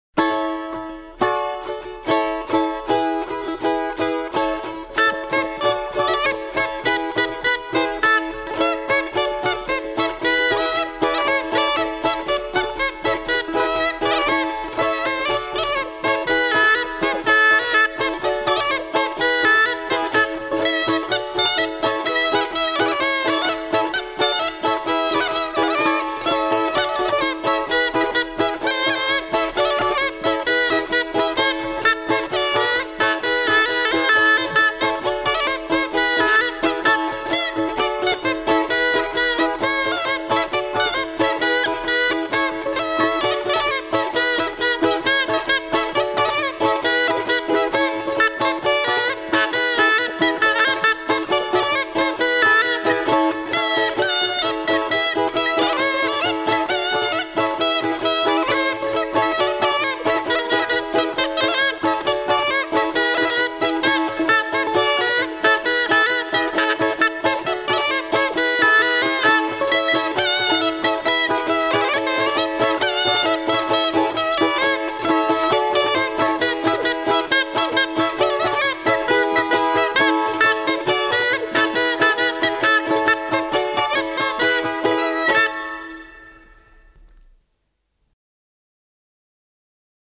Голоса уходящего века (Курское село Илёк) Камаринская (балалайка, рожок, инструментальный наигрыш)